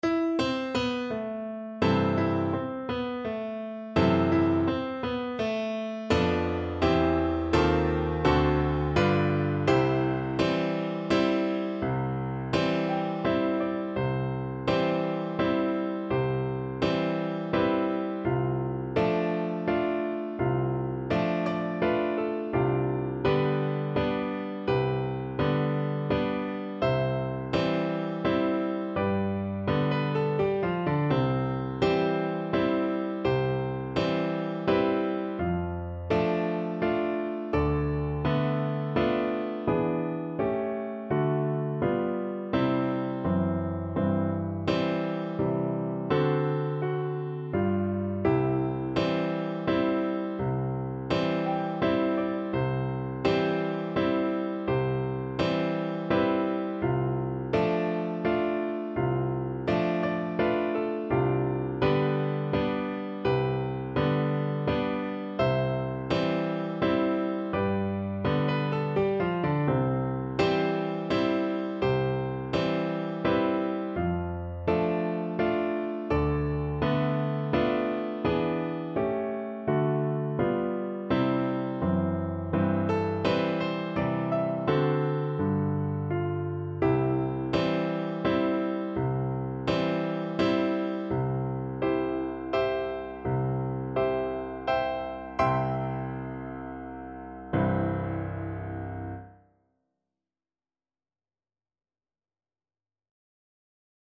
= 84 Andante non troppe e molto maestoso
3/4 (View more 3/4 Music)
Classical (View more Classical Bassoon Music)